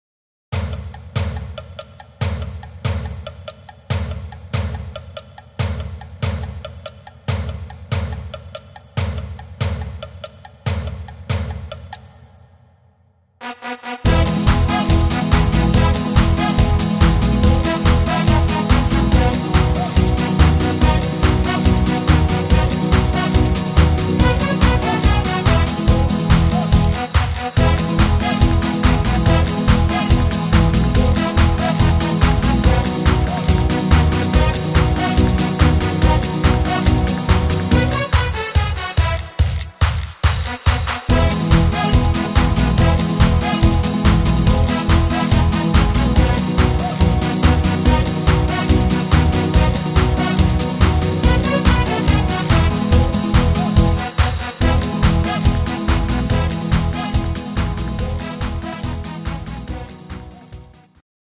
instr. Brass